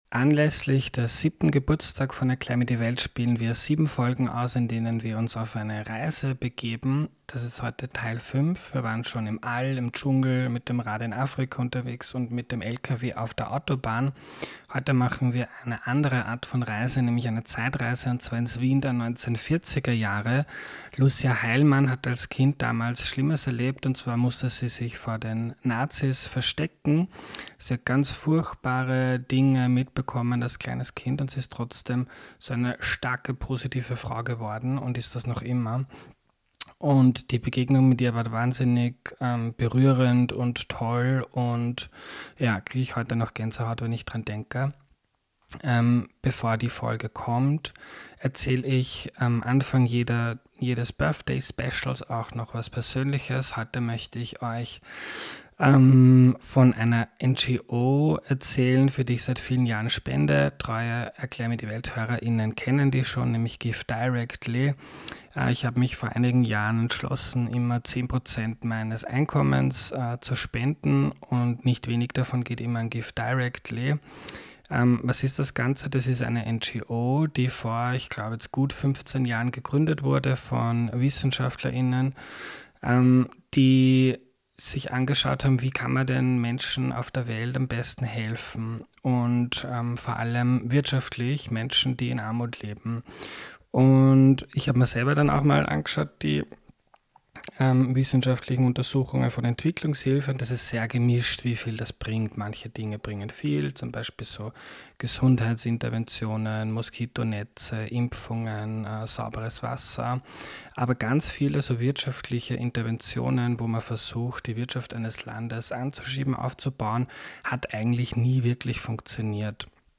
Beatbox am Ende